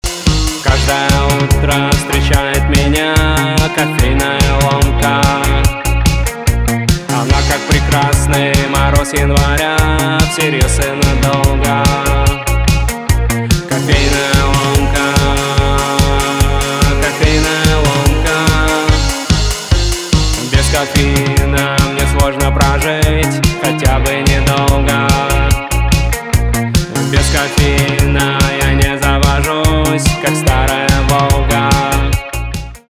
инди
гитара , барабаны